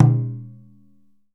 DJUN DJUN04L.wav